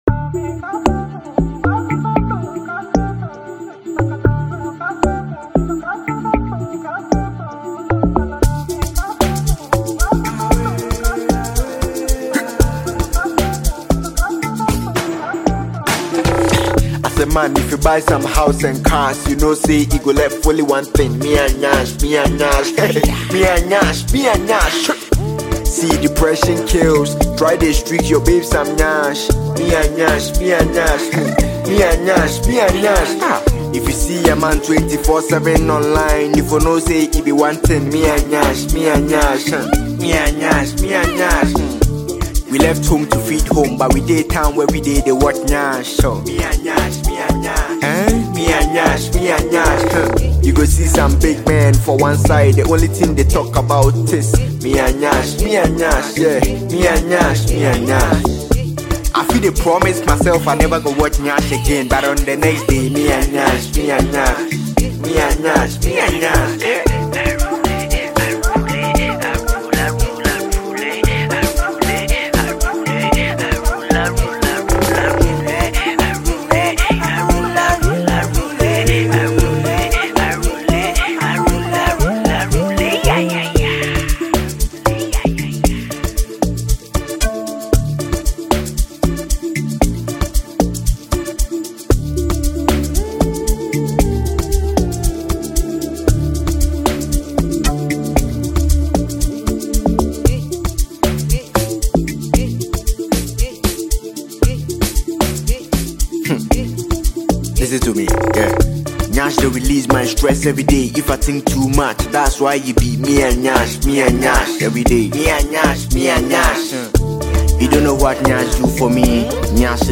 infectiously sweet new tune